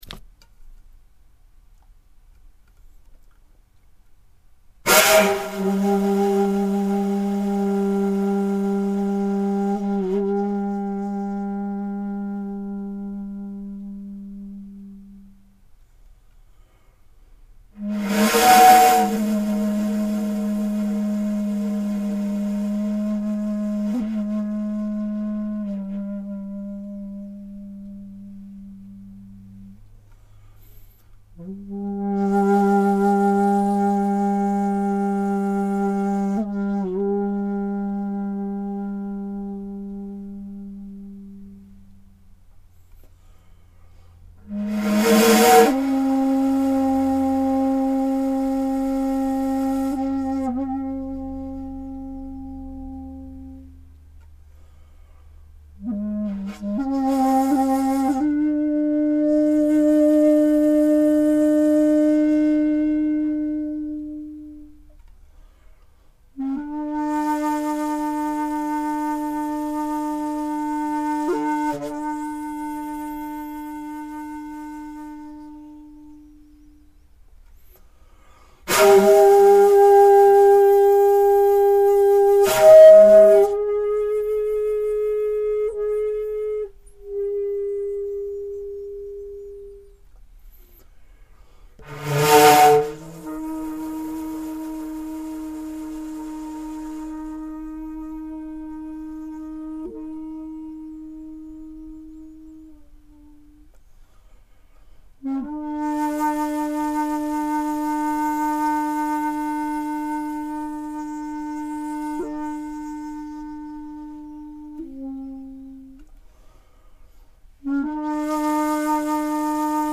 traditional Japanese bamboo flute